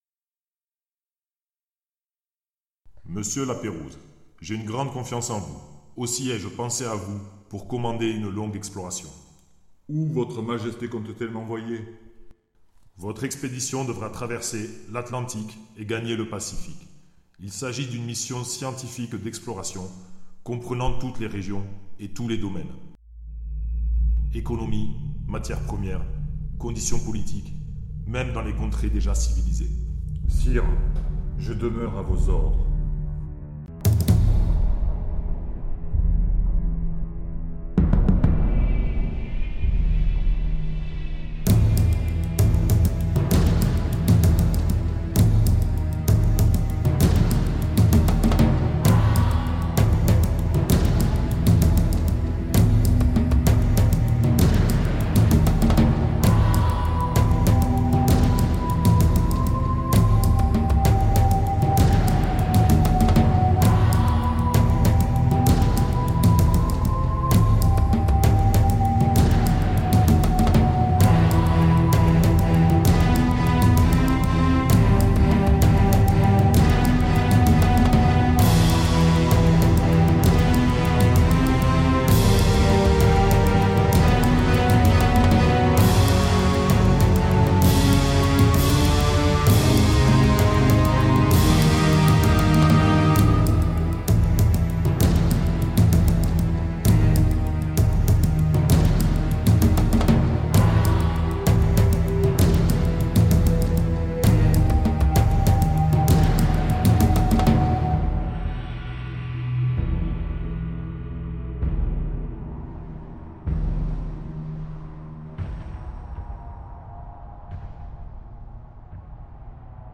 Dialogue fictif entre le roi et Lapérouse.